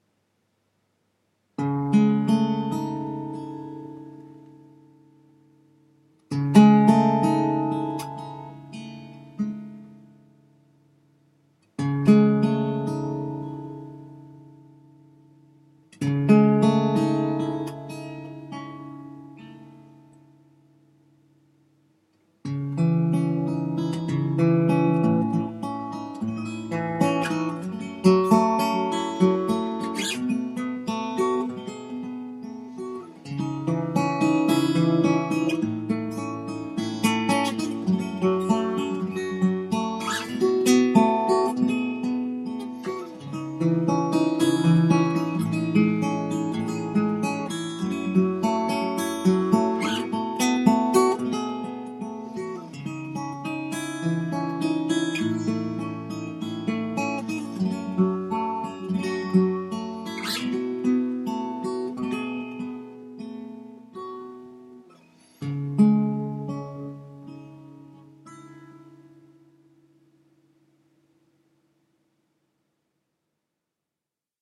composed an accompanying score for acoustic guitar to run alongside the image projection and reading